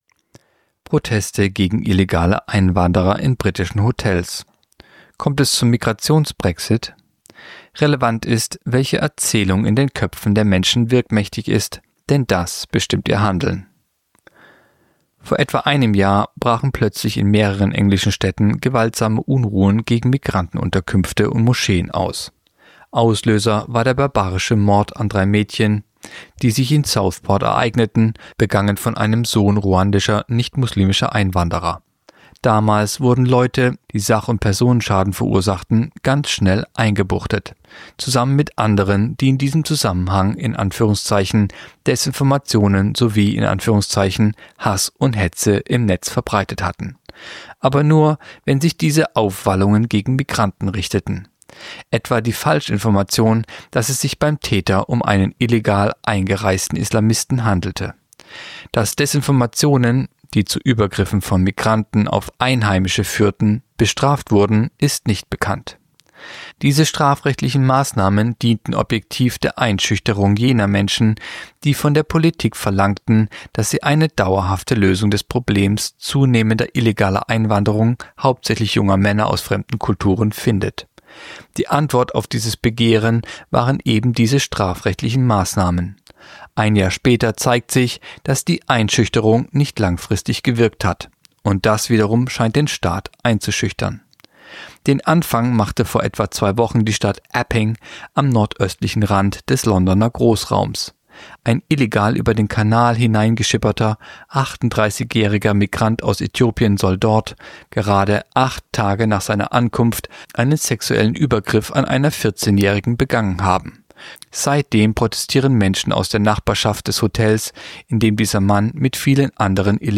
(Sprecher)